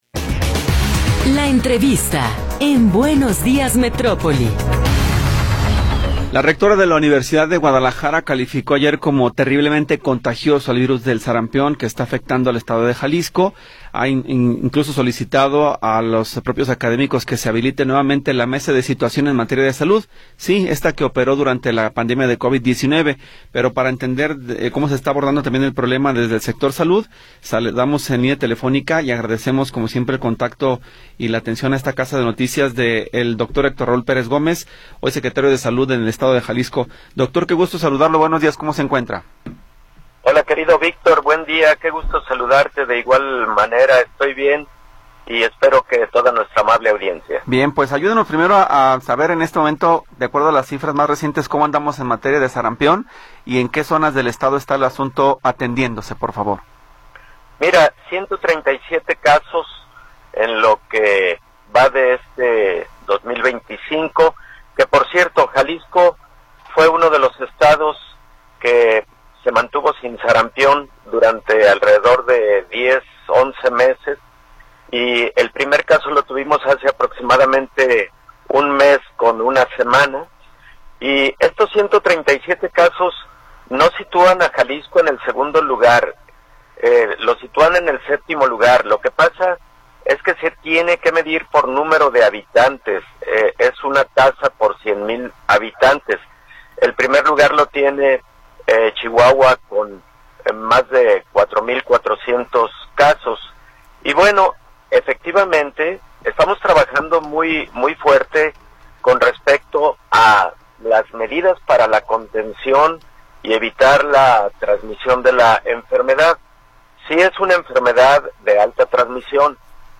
Entrevista con el Dr. Héctor Raúl Pérez Gómez